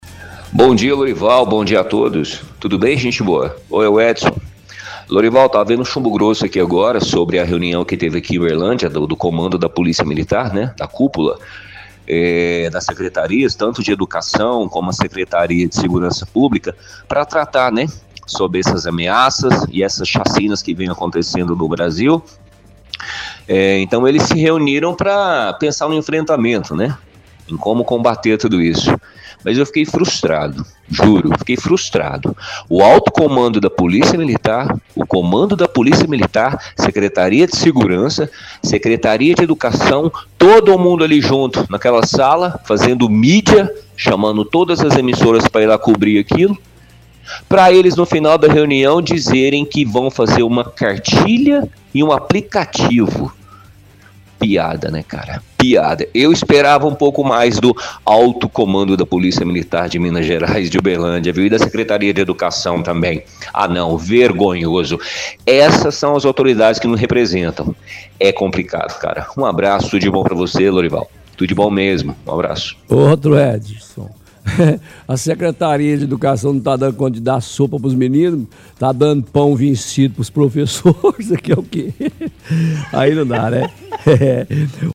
– Ouvinte critica reunião envolvendo a secretaria de educação e forças de segurança debochando das medidas anunciadas e afirmando que esperava mais da polícia e da secretaria de educação.